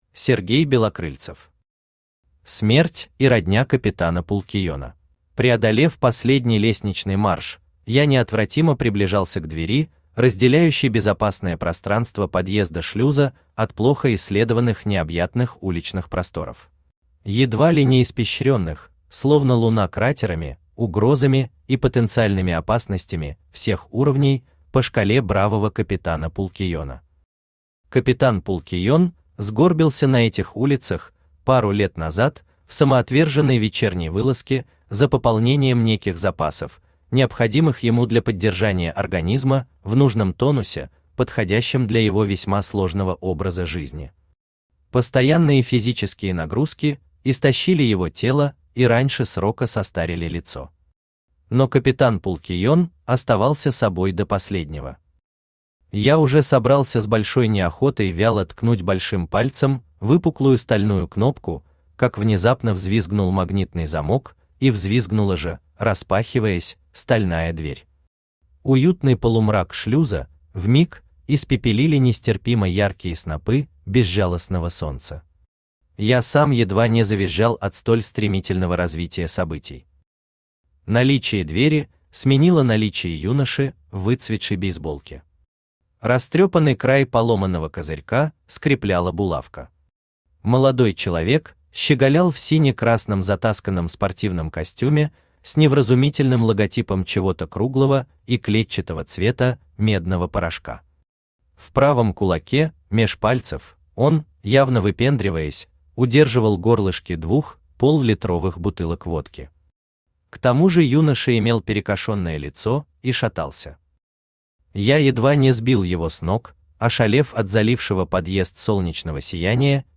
Аудиокнига Смерть и родня капитана Пулкиена | Библиотека аудиокниг
Aудиокнига Смерть и родня капитана Пулкиена Автор Сергей Валерьевич Белокрыльцев Читает аудиокнигу Авточтец ЛитРес.